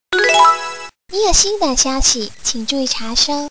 NewMessage.mp3